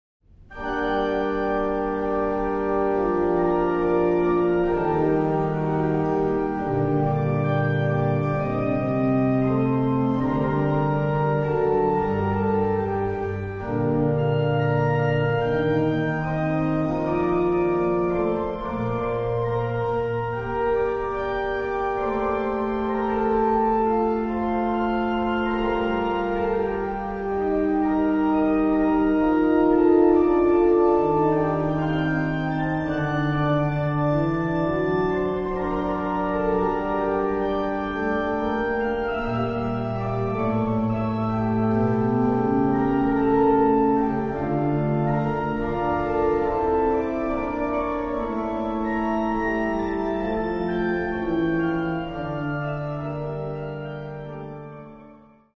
Organo "Amedeo Ramasco", Chiesa Parrocchiale di Crocemosso
Organo